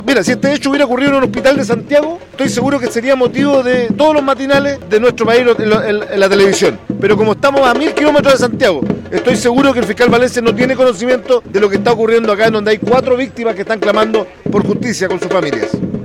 Hasta el lugar también llegó el senador, Fidel Espinoza, señalando que esta semana tendrá una reunión con el Fiscal Nacional Ángel Valencia por los 4 presuntos casos de abuso sexual y violación en investigación.
senador-cuna.mp3